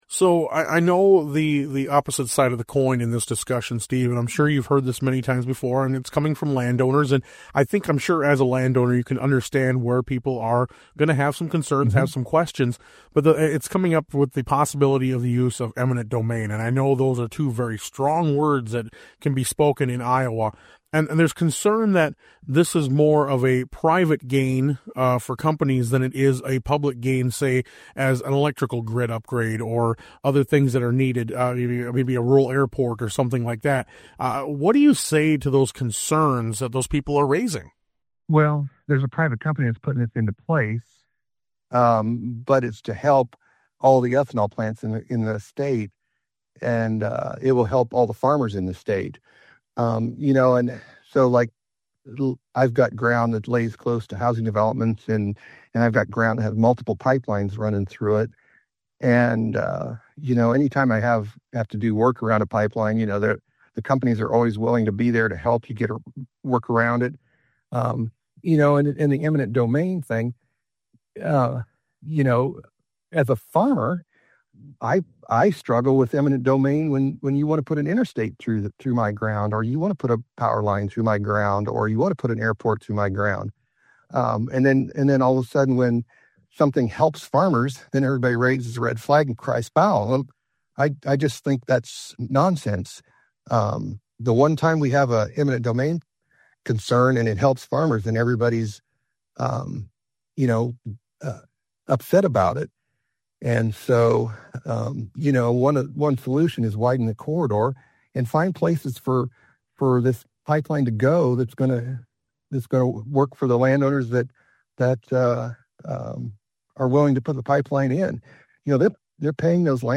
Featured Conversation: ICGA addresses land use concerns